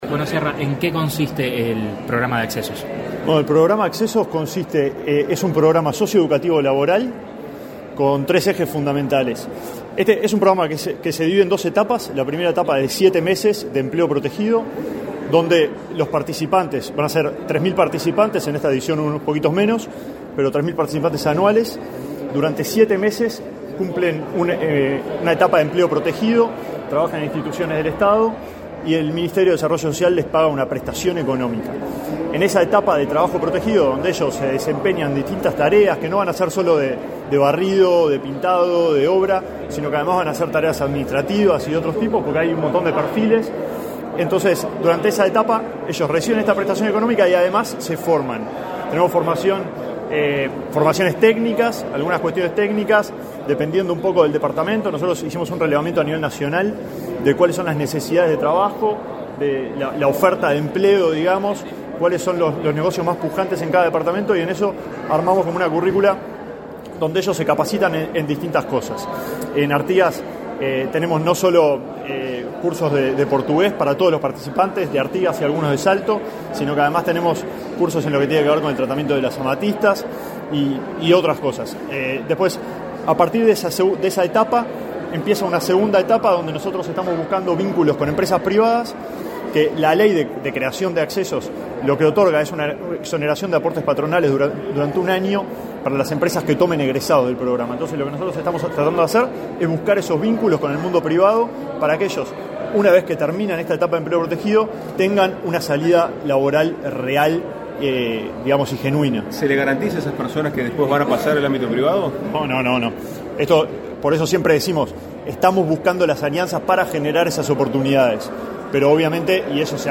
Declaraciones a la prensa del director de Gestión Territorial del Mides, Alejandro Sciarra
Tras el acto, el jerarca efectuó declaraciones a la prensa.